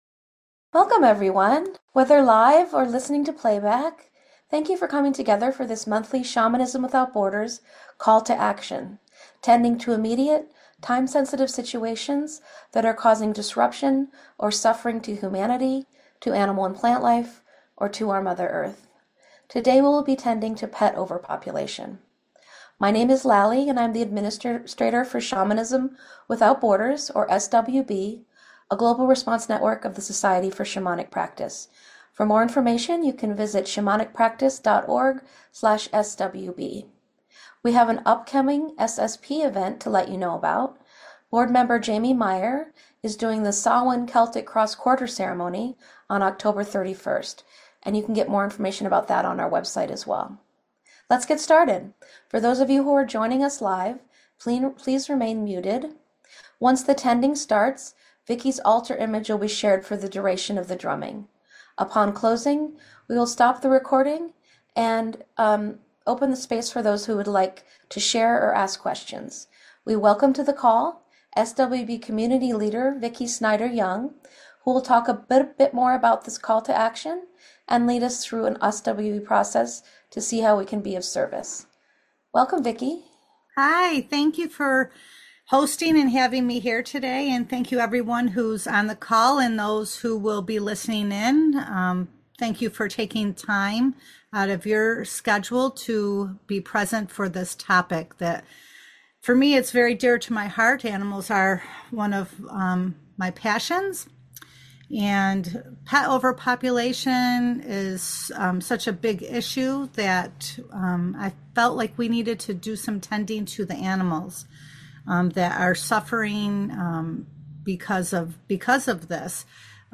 You are welcome to rattle or drum along during the shamanic journey process.